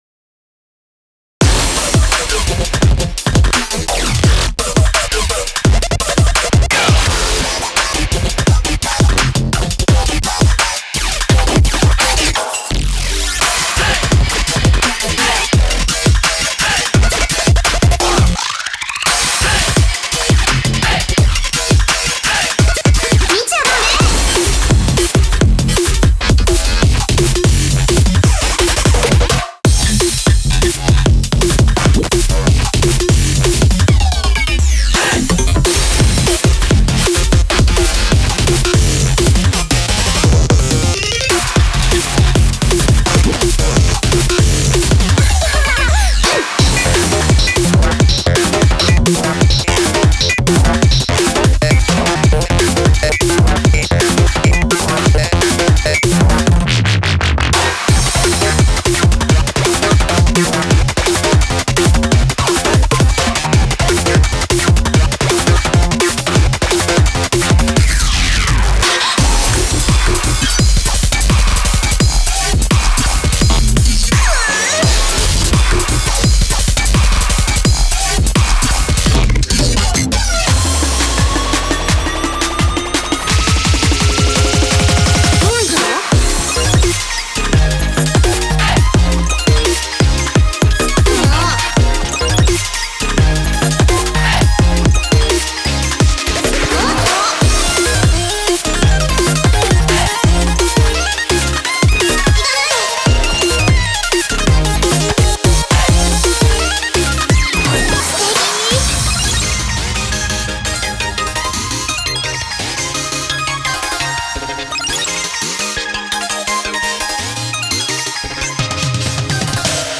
BPM170
Audio QualityPerfect (High Quality)